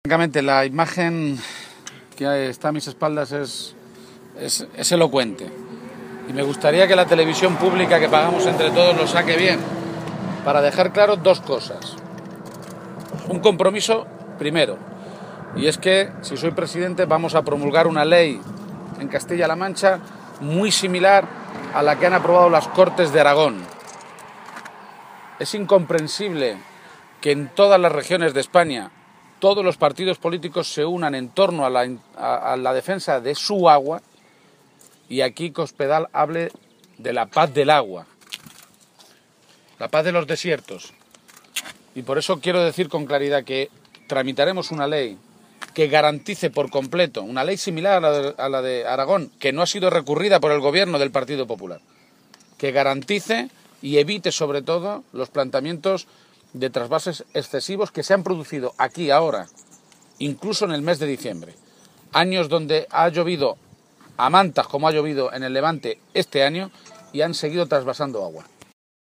García-Page se pronunciaba de esta manera esta mañana, en la localidad alcarreña de Sacedón, donde se ubica el pantano de Entrepeñas, uno de los dos grandes pantanos de cabecera del Tajo de los que sale el agua para el trasvase al Segura.